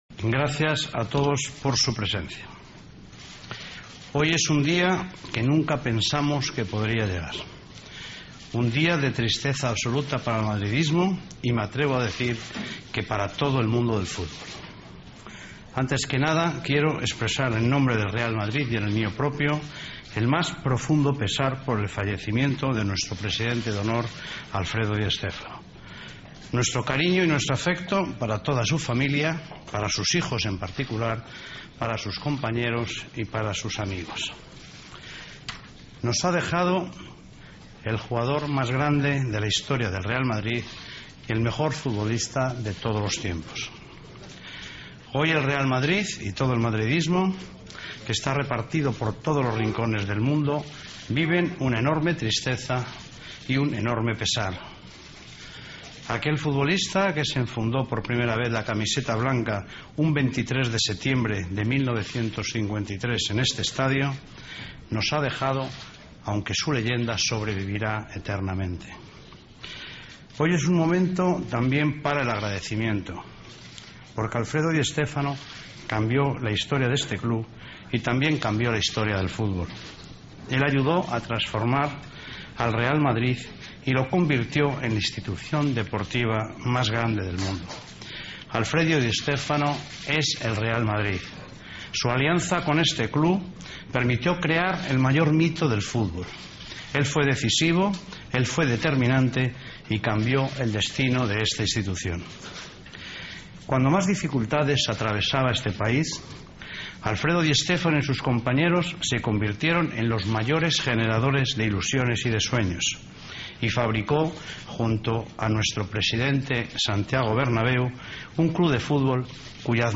AUDIO: Emocionante comparecencia del presidente del Real Madrid el día que murió el presidente de honor del club blanco